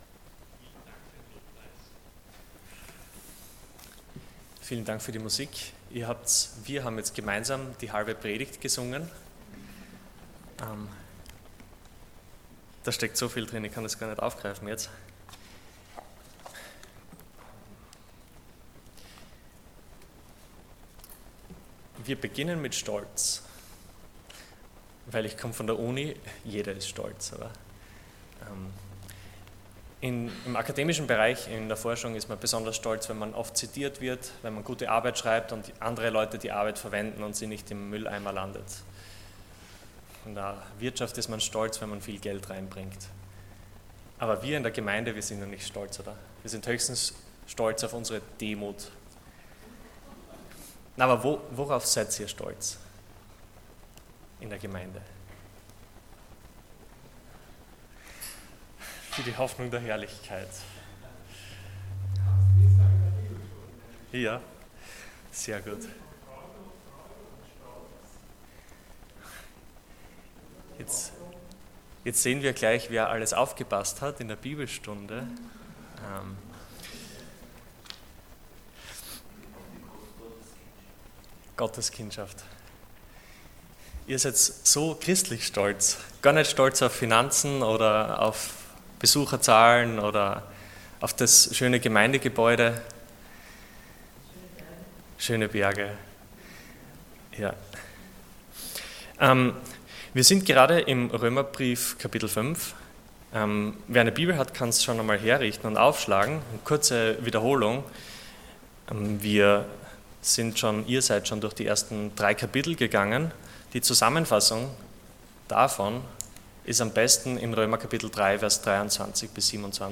Passage: Romans 5:1-11 Dienstart: Sonntag Morgen